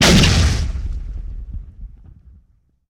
punch3.ogg